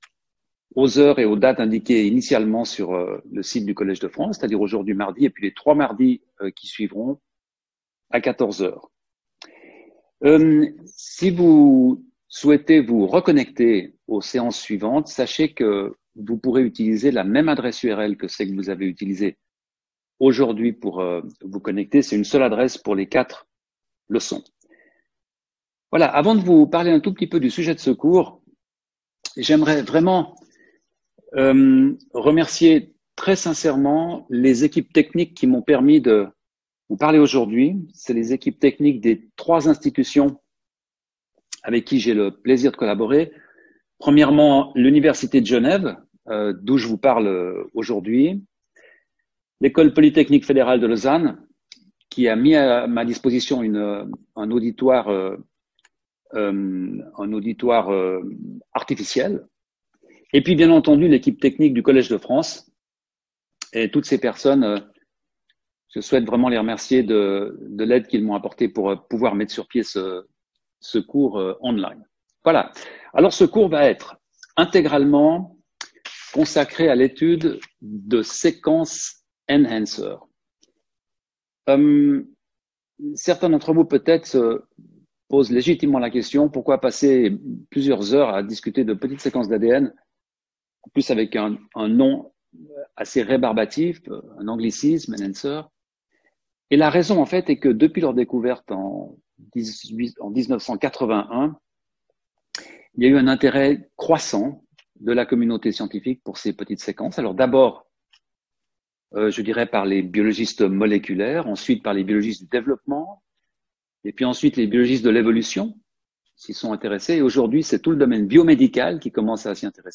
Sauter le player vidéo Youtube Écouter l'audio Télécharger l'audio Lecture audio Dans ce premier cours, le Pr Denis Duboule présente le contexte général et historique qui explique les raisons pour lesquelles ces petites séquences d’ADN sont devenues un objet d’études aujourd’hui très important.